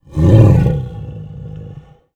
MONSTERS_CREATURES
MONSTER_Growl_Breath_03_mono.wav